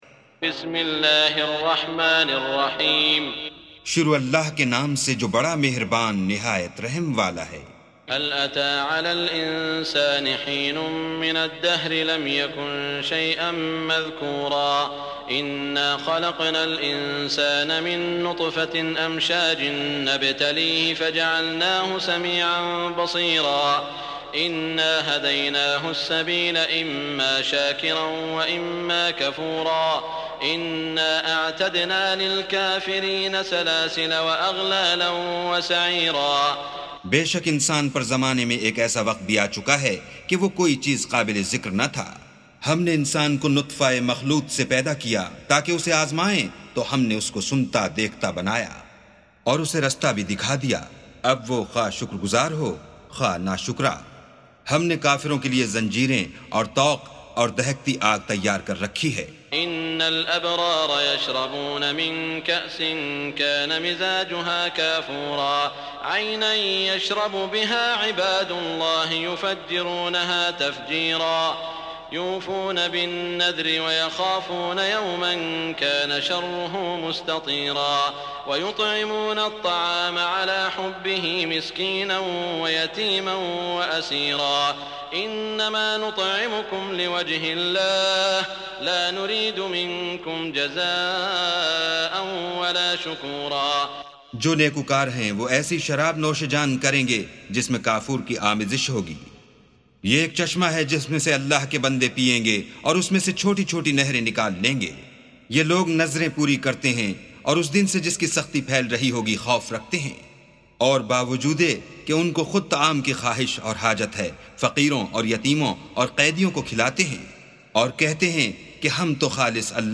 سُورَةُ الإِنسَانِ بصوت الشيخ السديس والشريم مترجم إلى الاردو